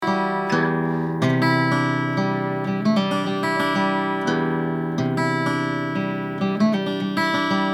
Ленточный микрофон "АПЛ" (Алюминиевая-Поталевая-Лента) лента 0,2 микрона.